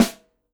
CYCdh_K4-Snr05.wav